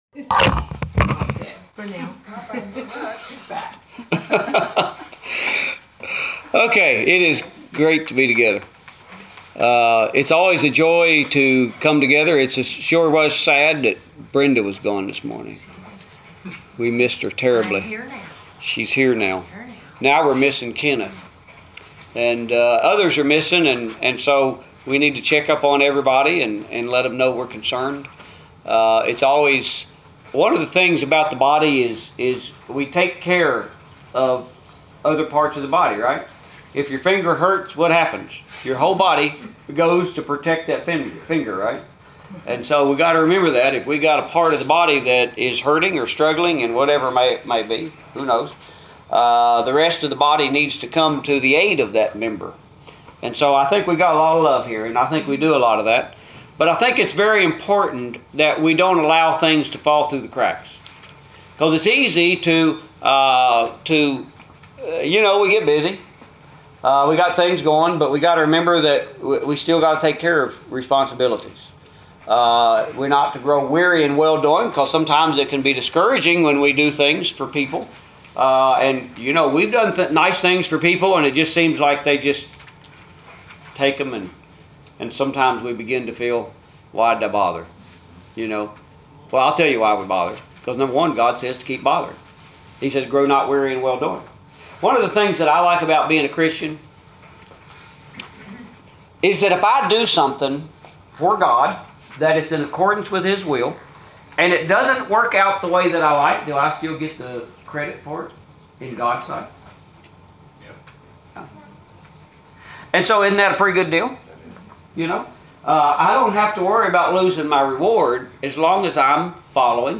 Authority Part 2 authority , Bible , scripture , sermon Post a comment Cancel Reply You must be logged in to post a comment.